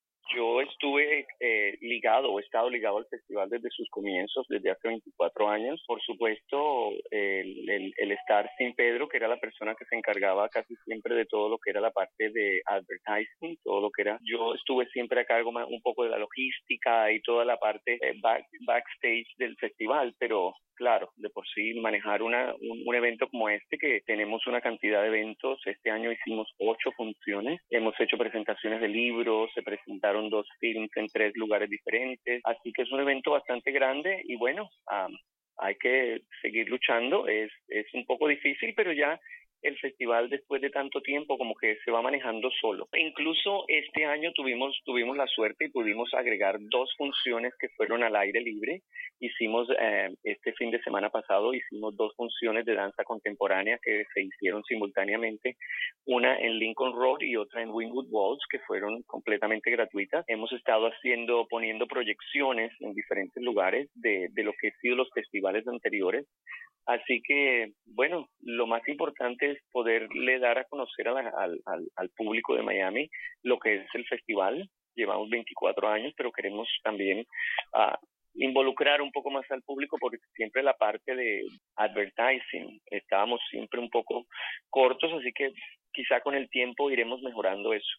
Fragmento entrevista